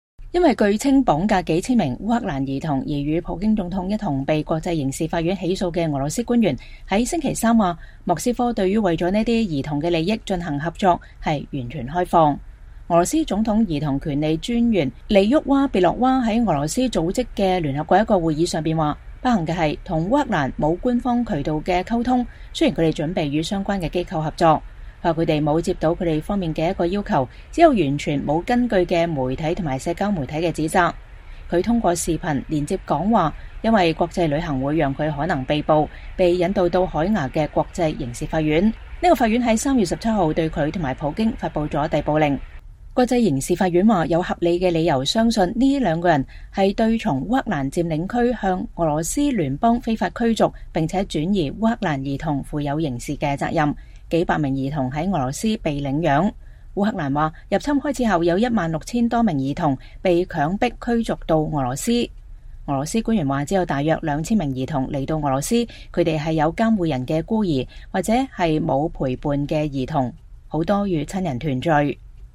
俄羅斯總統兒童權利專員利沃娃-別洛娃在聯合國安理會非正式會議上發表視頻講話。（2023年4月5日）